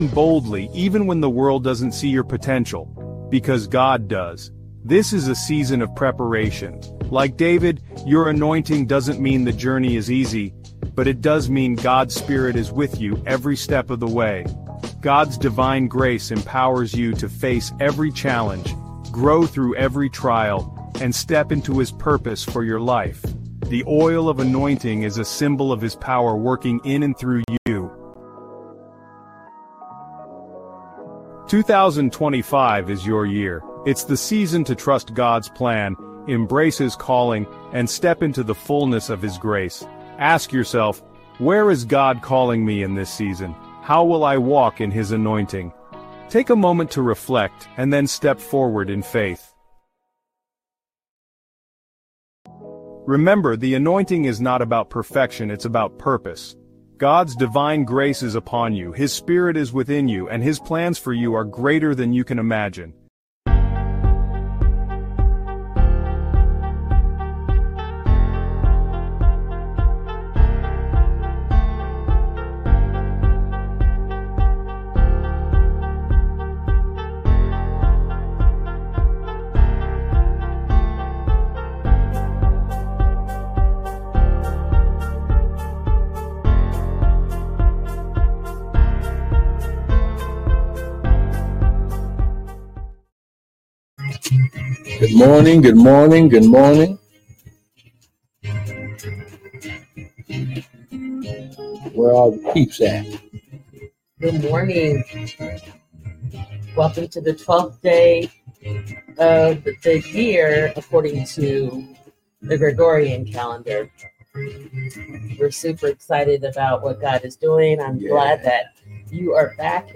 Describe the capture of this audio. live-recording 4/26/2024 7:03:15 AM. live-recording 4/25/2024 6:47:11 AM.